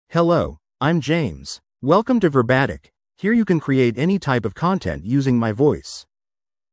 James — Male English (United States) AI Voice | TTS, Voice Cloning & Video | Verbatik AI
MaleEnglish (United States)
James is a male AI voice for English (United States).
Voice sample
Male
James delivers clear pronunciation with authentic United States English intonation, making your content sound professionally produced.